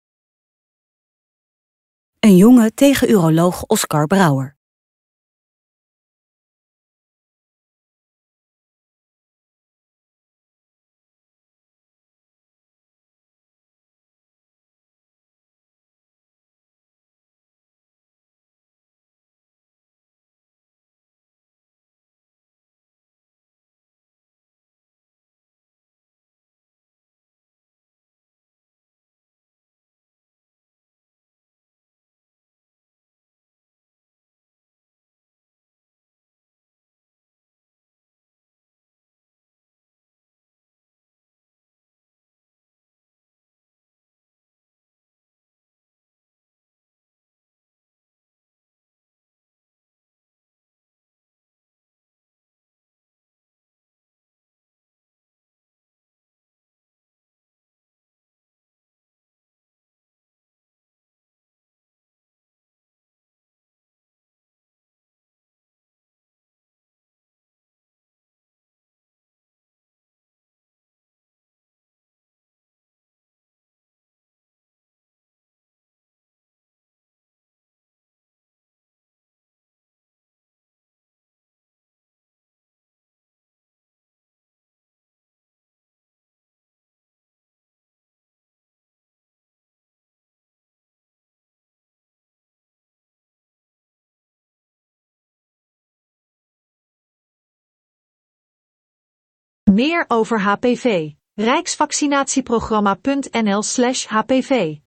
Rustige muziek
Twee mannen lopen een set op.